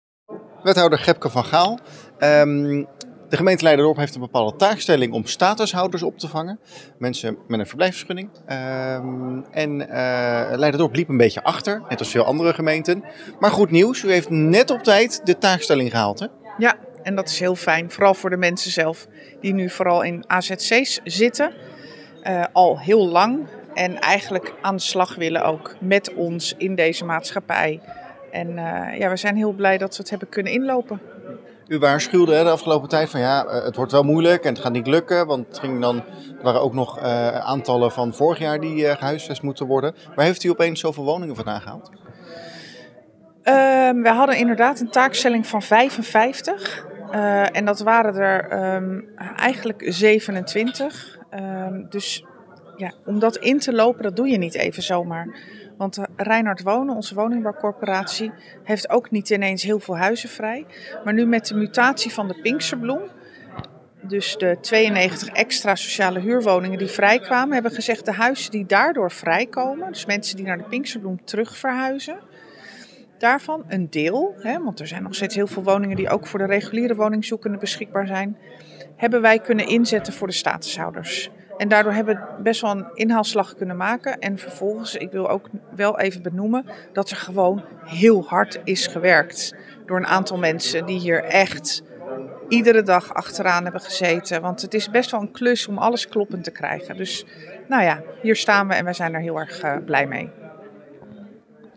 AUDIO: Wethouder Gebke van Gaal over het huisvesten van statushouders.